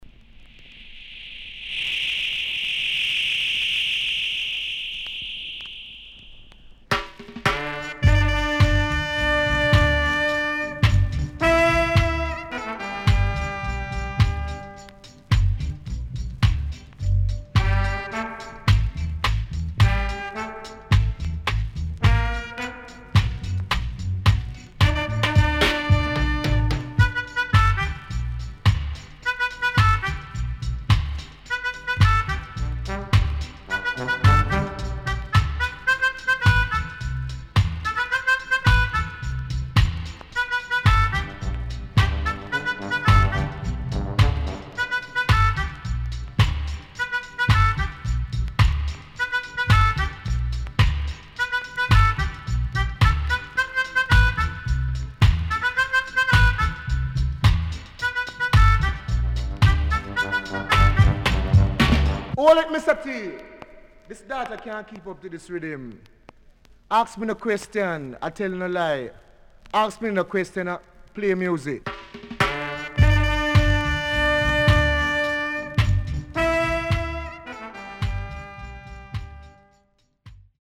Nice Vocal
SIDE A:所々チリノイズがあり、少しプチノイズ入ります。